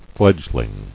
[fledg·ling]